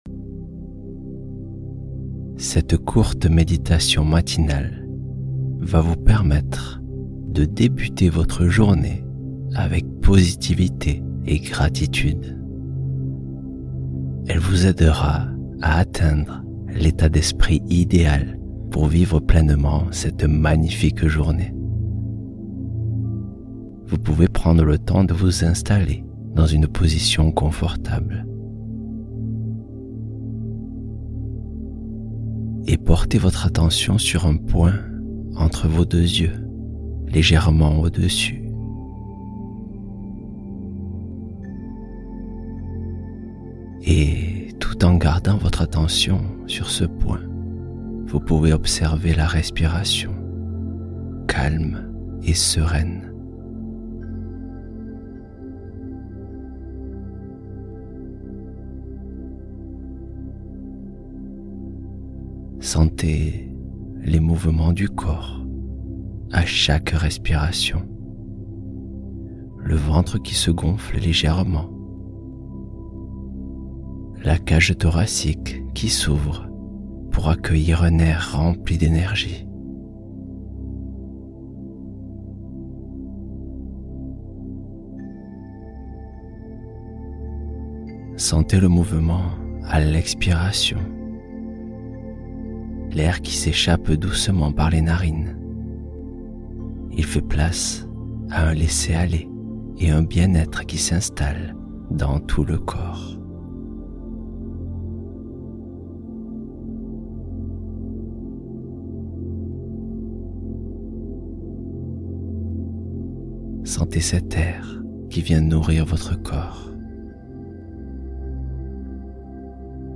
Réveillez La Paix Intérieure Qui Sommeille En Vous | Méditation Matinale Positivité